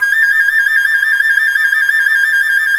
Index of /90_sSampleCDs/Roland LCDP04 Orchestral Winds/FLT_C Flute FX/FLT_C Flt Trill